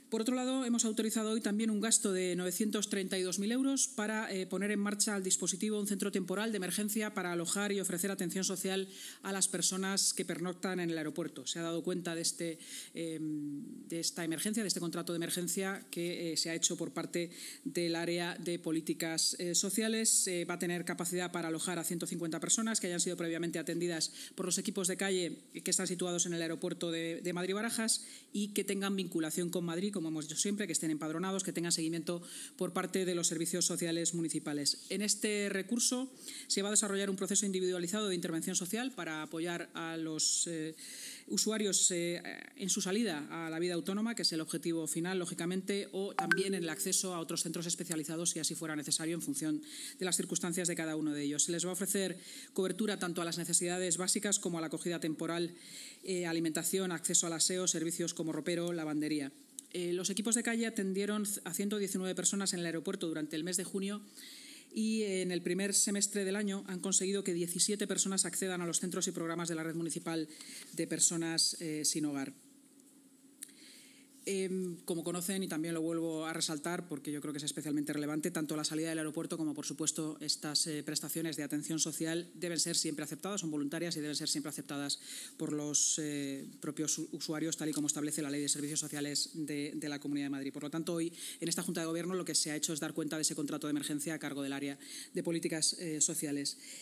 Nueva ventana:La vicealcaldesa y portavoz municipal, Inma Sanz: